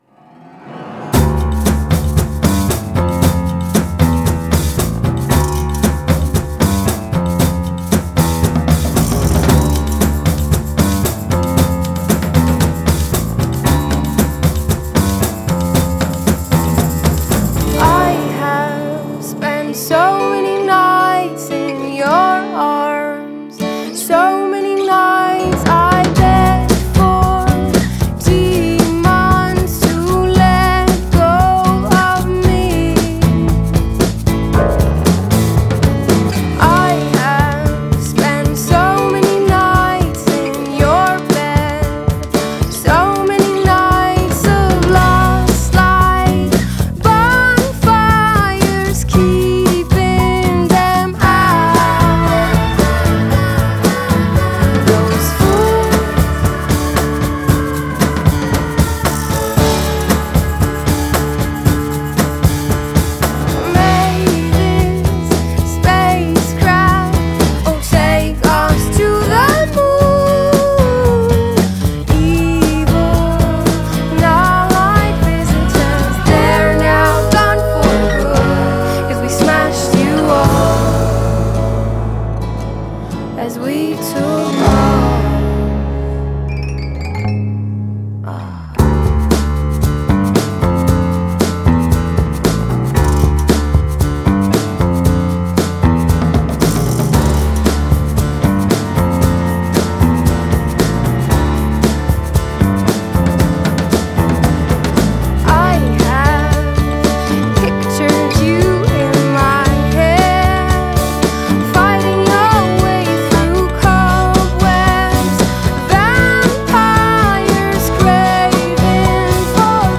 the lightly distorted guitars